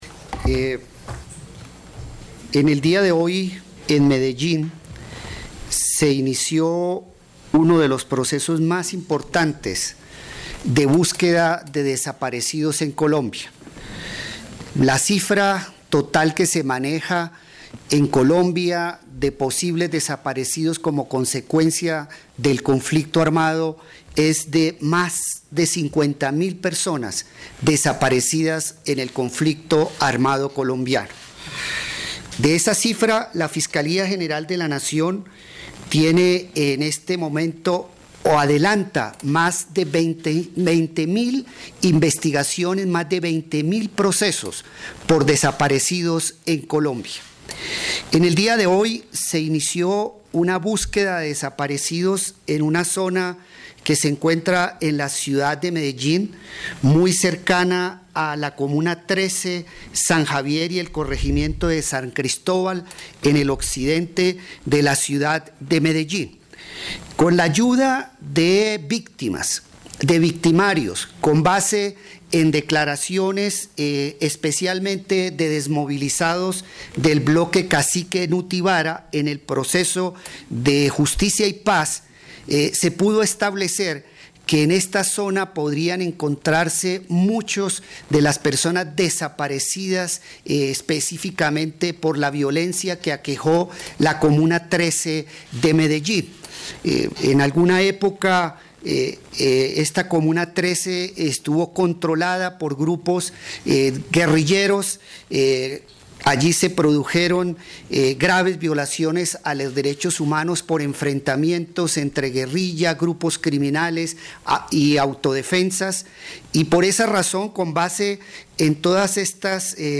Rueda de prensa Eduardo Montealegre Lynett, Fiscal General de la Nación